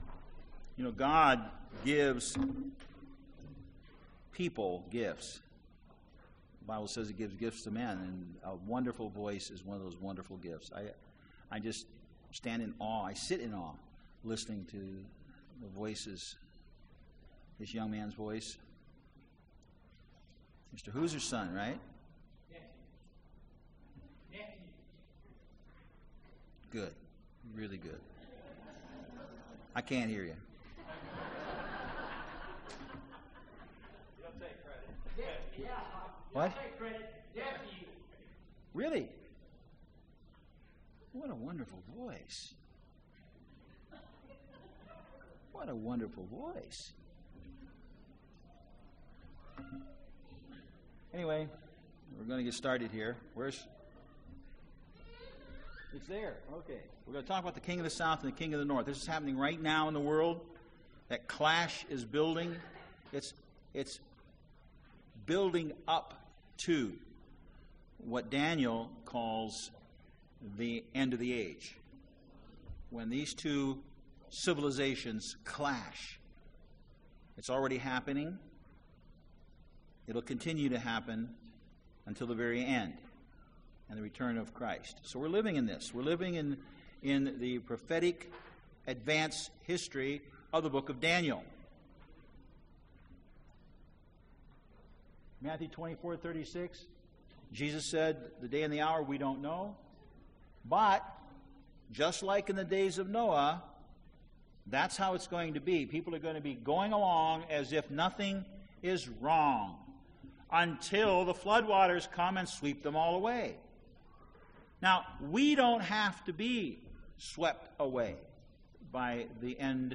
Print The clash of the King of the North with the King of the South UCG Sermon Studying the bible?
Given in Dallas, TX